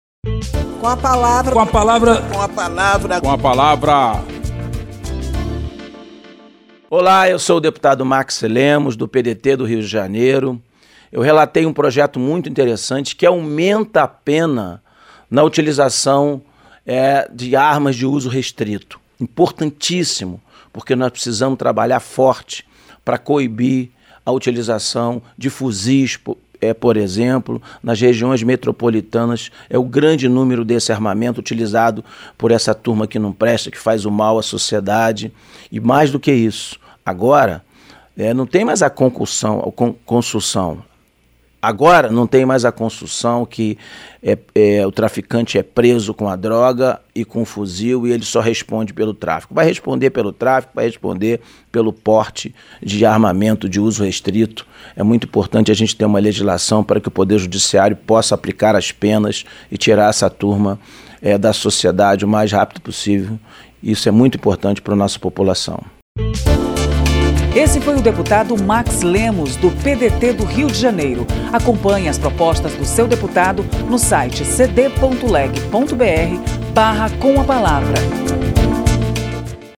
O relator do projeto que aumenta pena para crime envolvendo porte ilegal de arma de fogo de uso restrito, como o fuzil, deputado Max Lemos (PDT-RJ), defende a proposta de agravamentod da lei penal.
Espaço aberto para que cada parlamentar apresente aos ouvintes suas propostas legislativas